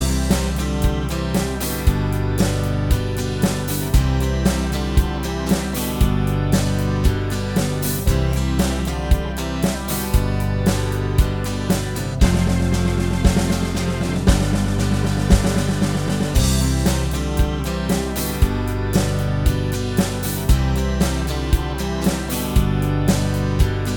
Pop (2000s)